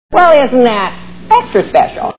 Saturday Night Live TV Show Sound Bites